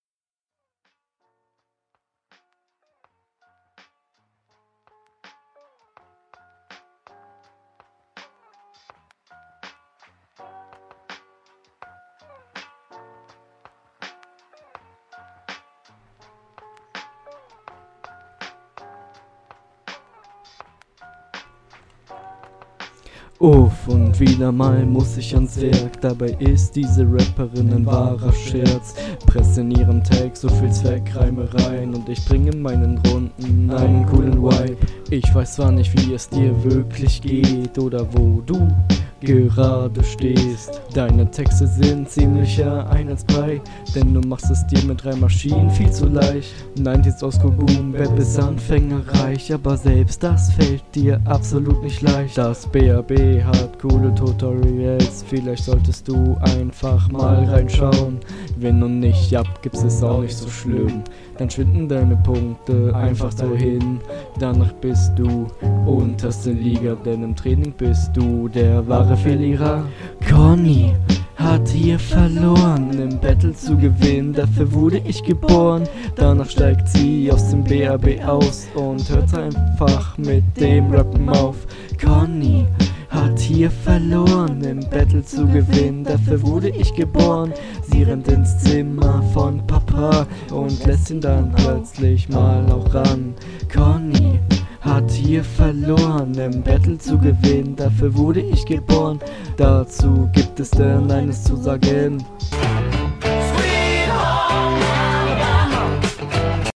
Flow: selbe Bild wie in den runden davor Text: manche Ansätze sind ganz okay aber …
Flow: Auch hier wieder ganz okay.